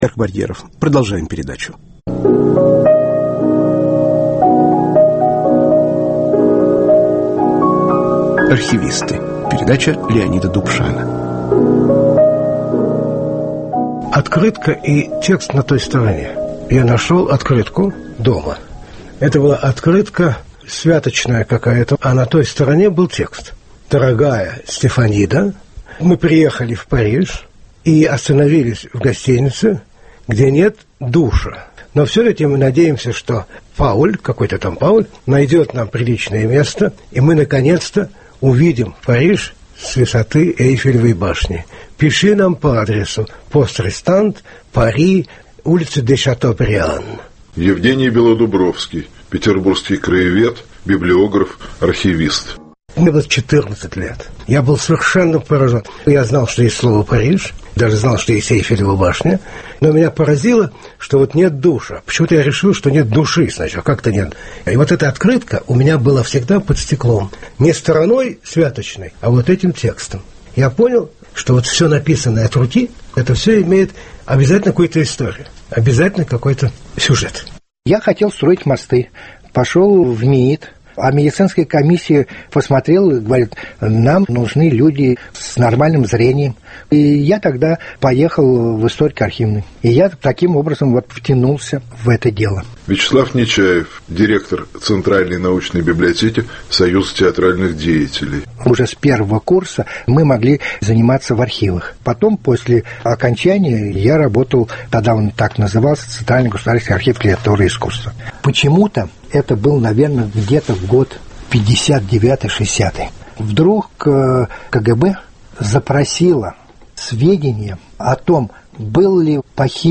О своём призвании говорят петербургские и московские архивисты. В передаче звучат редкие записи А.Кручёных и В.Набокова.